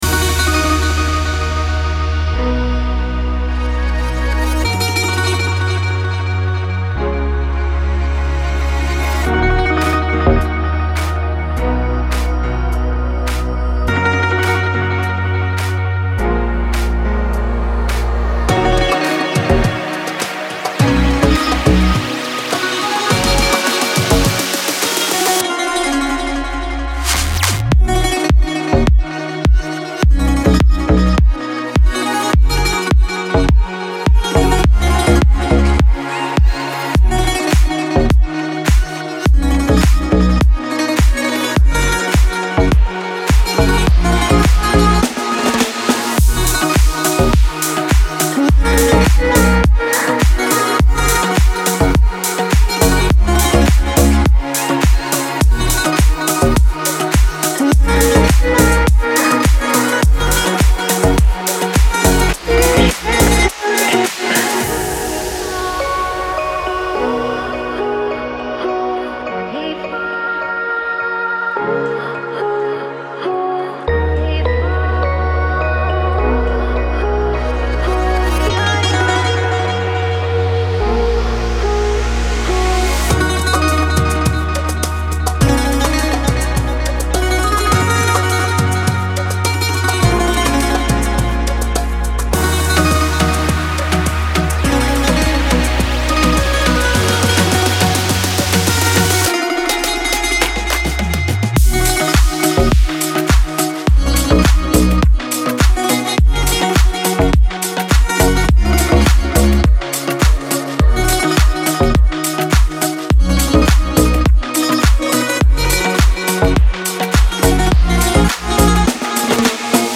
دیپ هاوس
سبک دیپ هاوس , ریتمیک آرام , موسیقی بی کلام